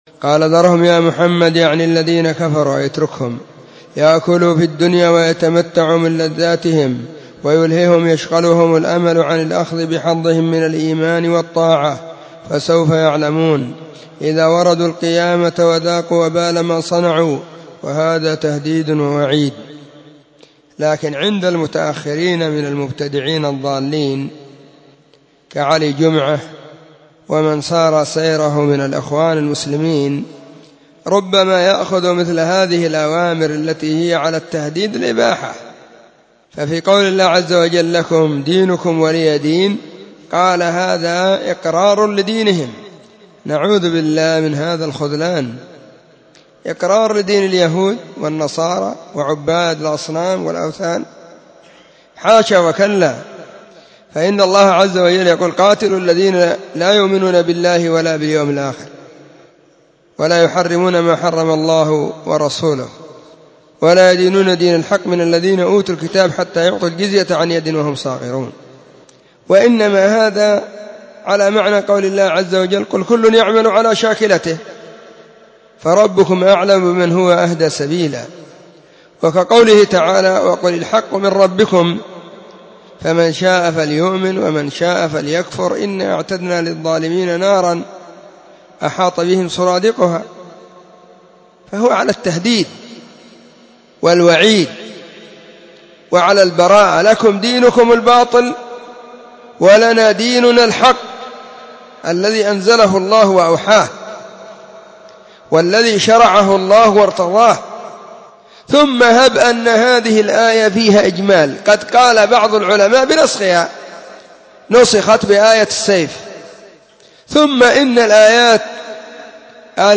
📢 مسجد الصحابة – بالغيضة – المهرة – اليمن حرسها الله.